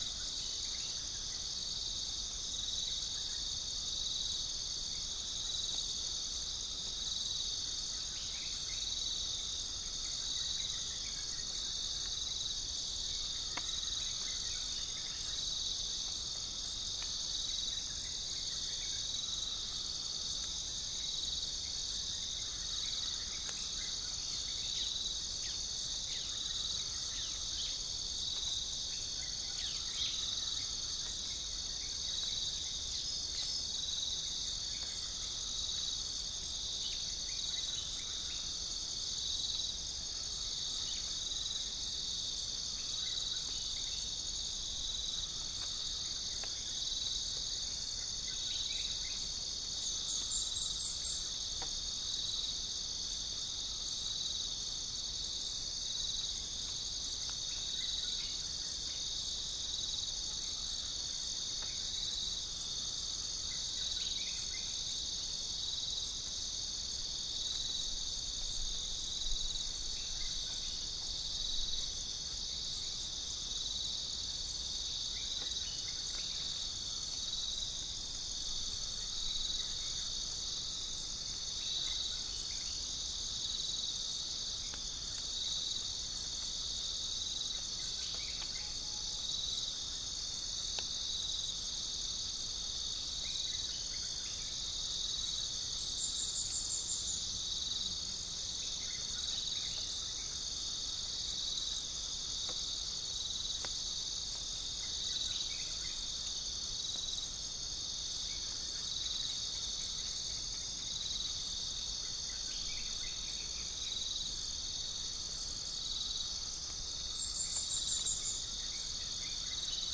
Gallus gallus
Centropus bengalensis
Pycnonotus goiavier
Prinia familiaris
Dicaeum trigonostigma